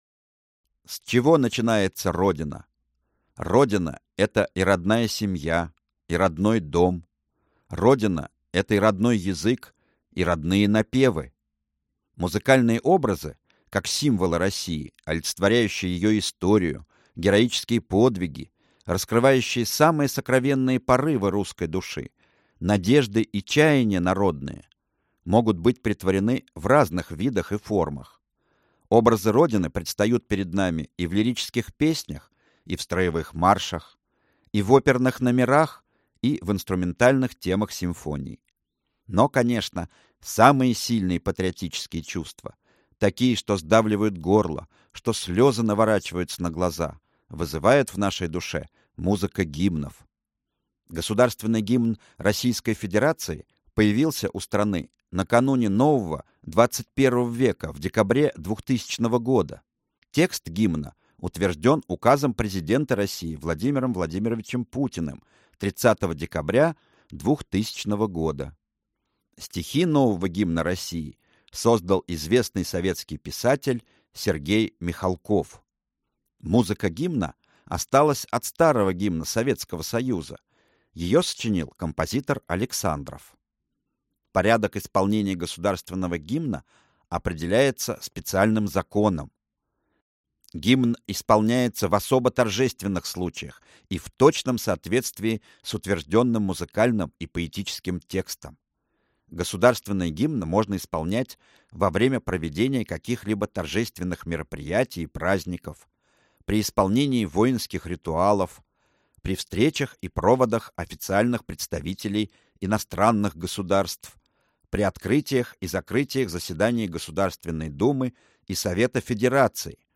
Аудиокнига Родина моя. Комплект учебных аудиопособий | Библиотека аудиокниг